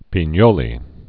(pēn-yōlē)